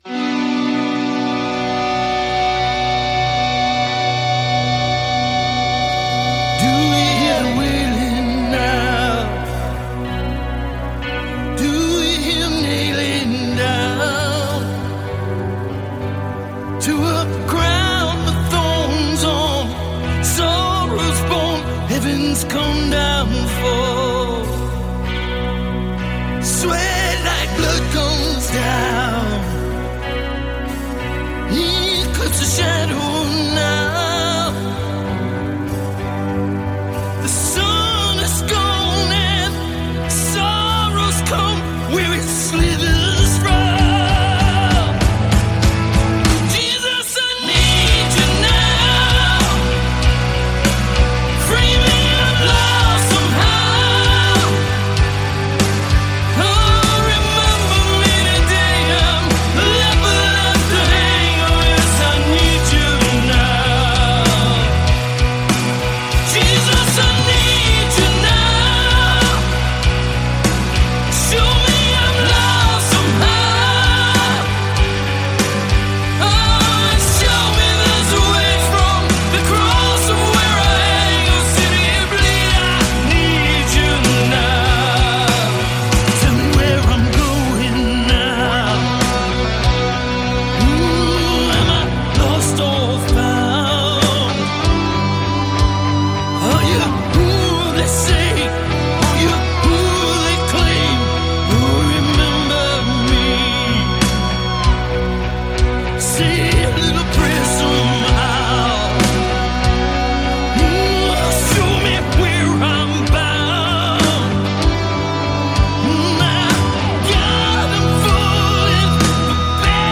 Жанр: Metal